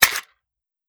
Foley / 5.56 M4 Rifle - Magazine Unloading 002.wav